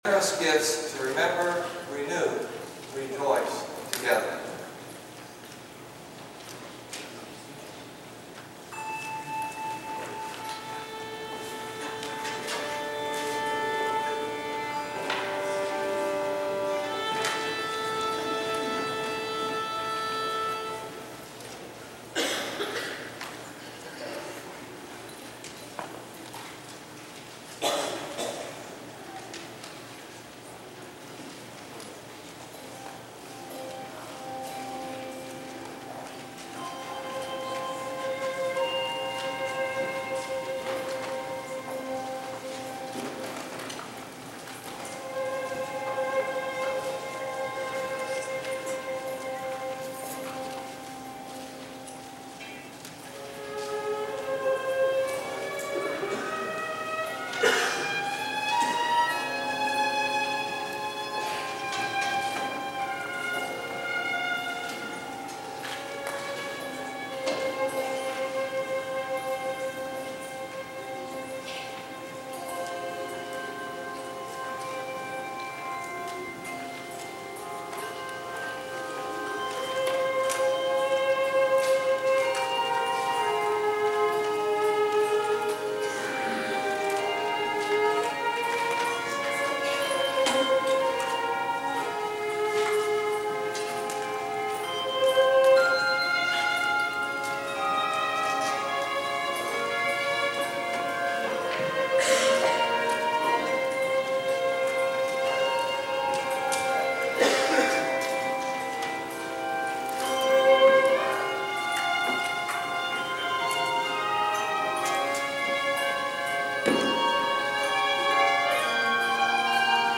Today’s blog is an audio blog, featuring sound clips from the service.
The next number I wanted to mention was that of the Jubilee Handbell Choir, that did a rendition of “It Is Well With My soul” by Bliss/Moklebust, that included the playing of an excellent violinist
service-bells-it-is-well.mp3